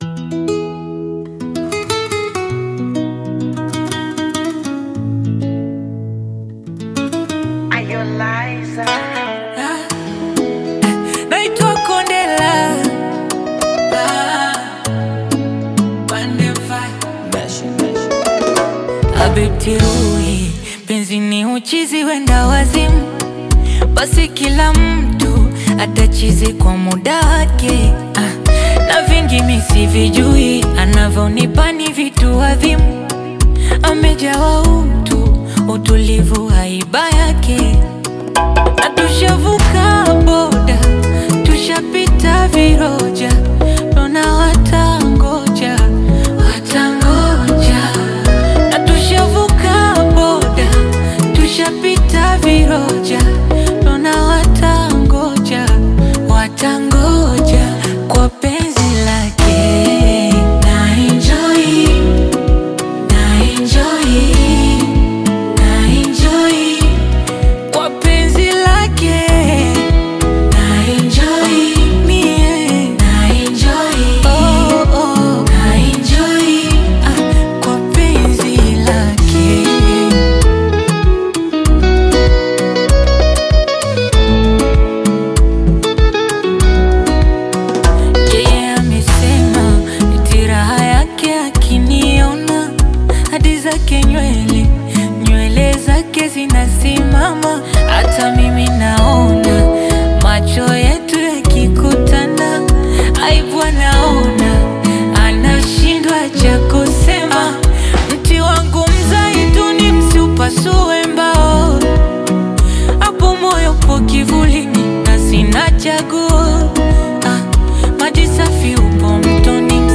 AudioBongo FlavaTanzanian Music